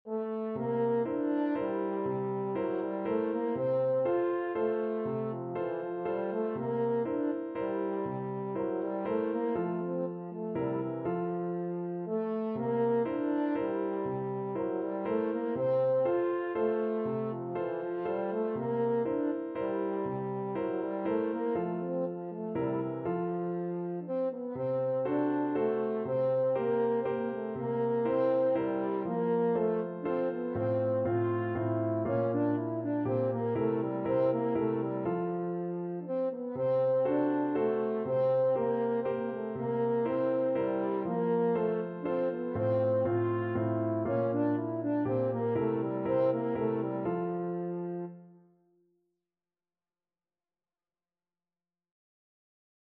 Free Sheet music for French Horn
French Horn
F major (Sounding Pitch) C major (French Horn in F) (View more F major Music for French Horn )
3/4 (View more 3/4 Music)
Classical (View more Classical French Horn Music)